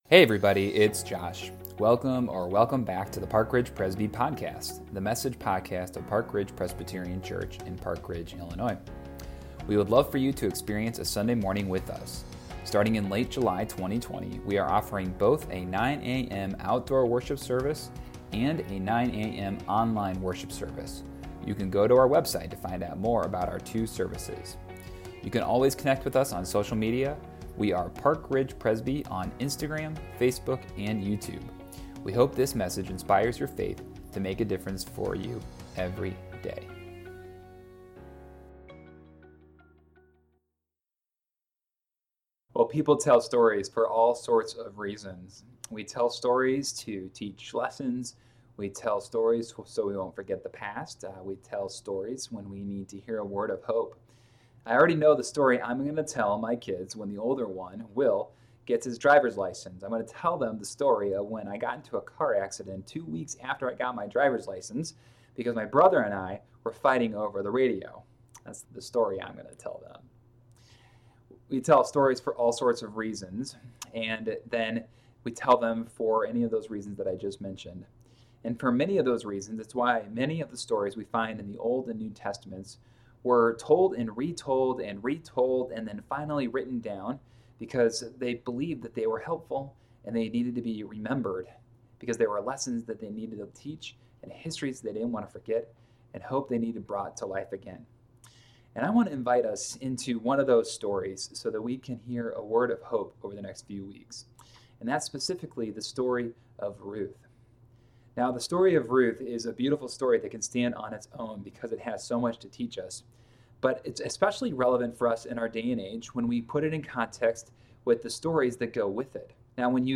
a message